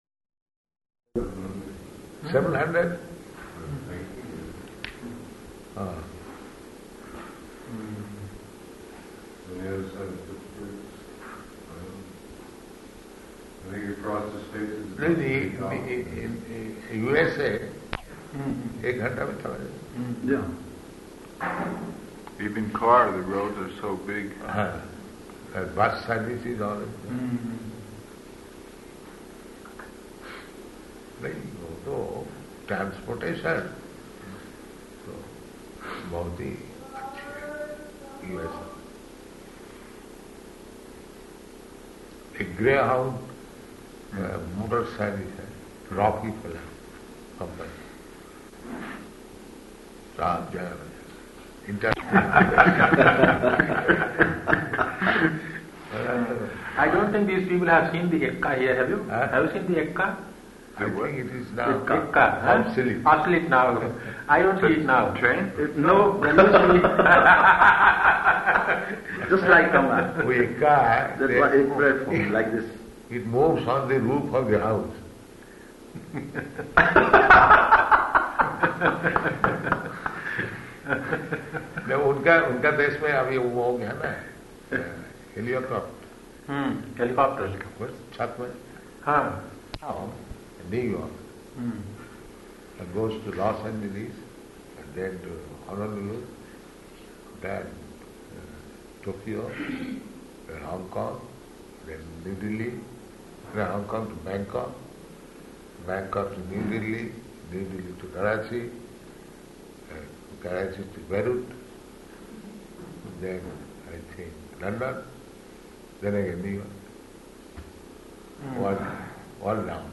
Room Conversation
Room Conversation --:-- --:-- Type: Conversation Dated: February 9th 1974 Location: Vṛndāvana Audio file: 740209R1.VRN.mp3 Prabhupāda: Seven hundred?